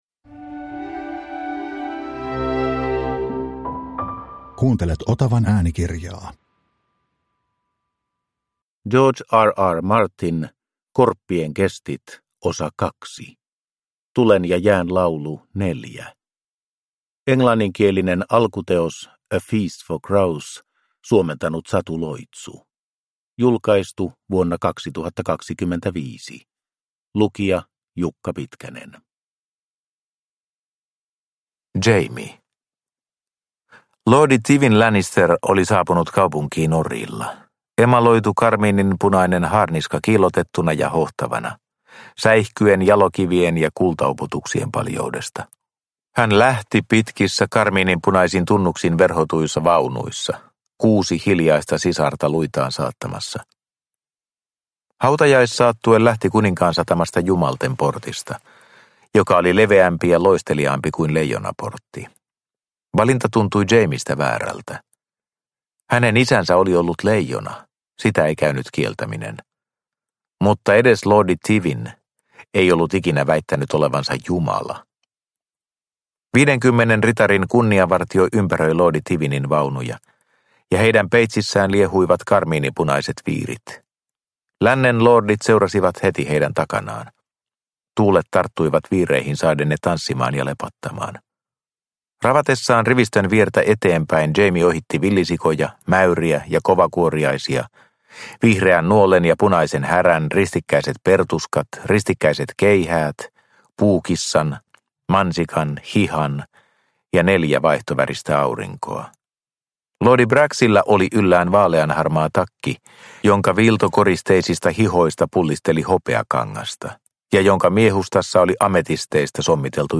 Korppien kestit 2 – Ljudbok